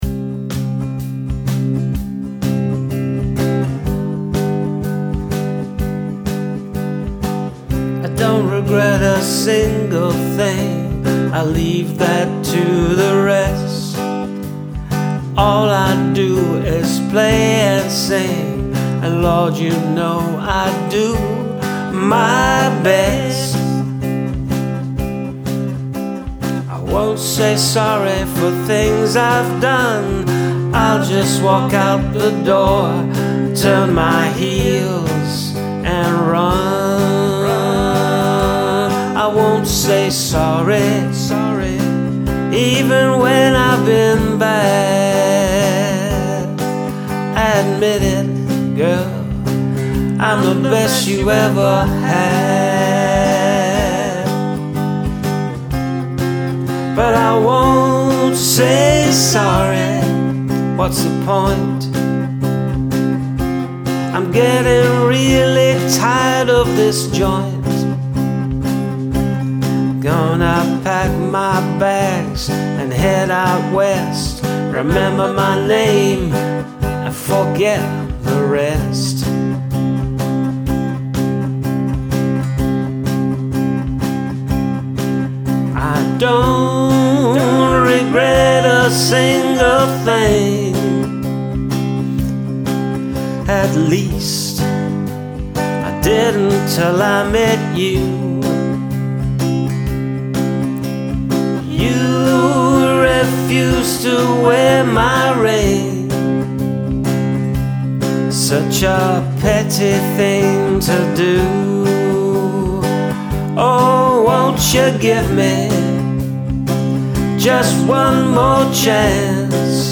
jauntier
some dodgy backing Vox thrown in at no extra charge...
Love the harmonies, and the cool pop lope this has.
Good classic country vibe.